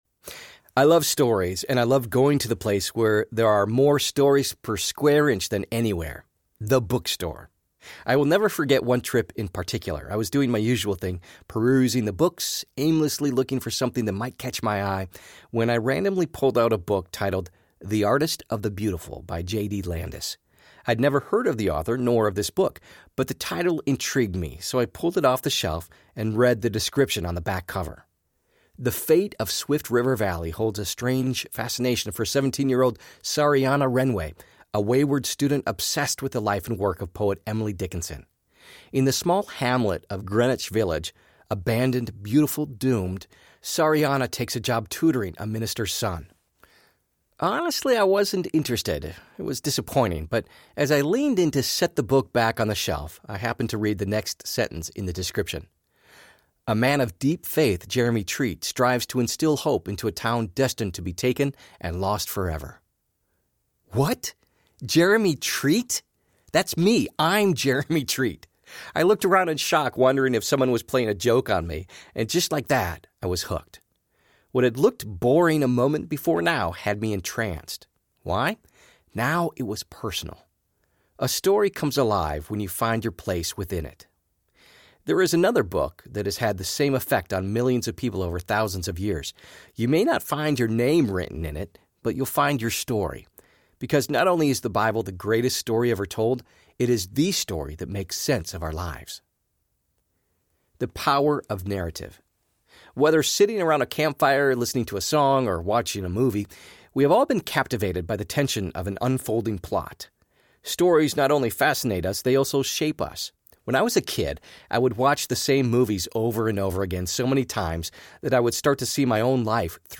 Seek First Audiobook
5.0 Hrs. – Unabridged